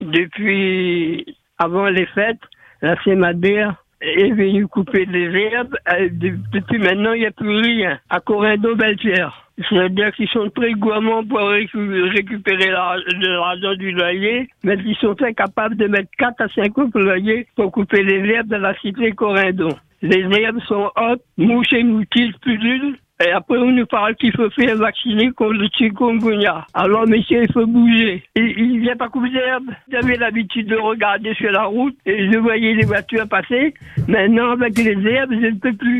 À Bellepierre, à la résidence Corindon, un habitant pousse un coup de gueule contre la Semader.